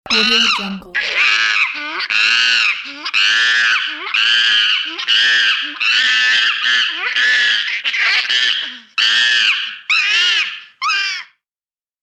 Monkey Screaming Bouton sonore